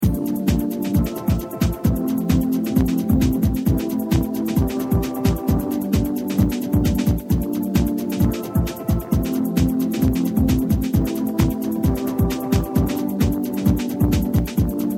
ハウスっぽい感じに仕上げてみました。キックのパターンがいかにもって感じです。
僕はパッド系の音色でコードを流した上にリズムを乗せるのが好きなんですよね。